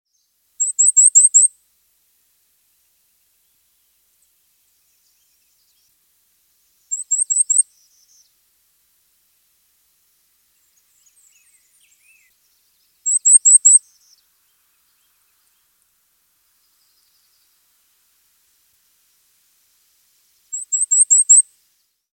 "Reinita Tigre"
"Cape May Warbler"
Dendroica tigrina
reinita-tigre.mp3